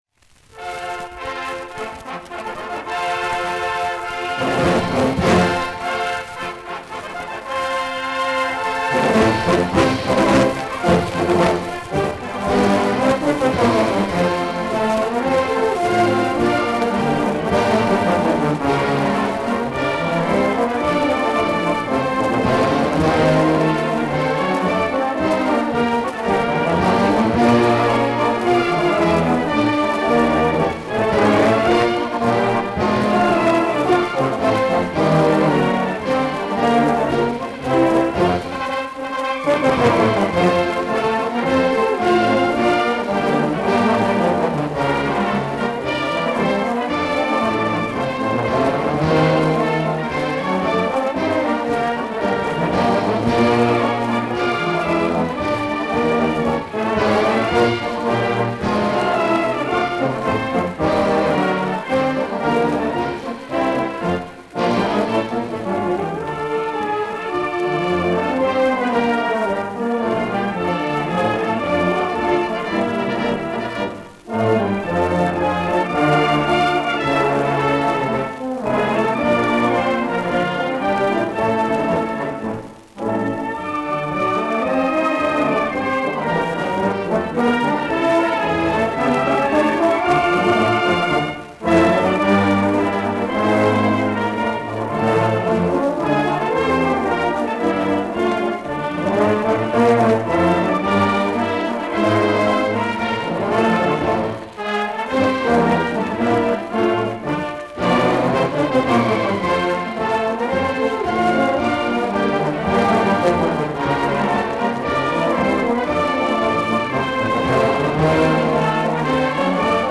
Попурри на темы трех песен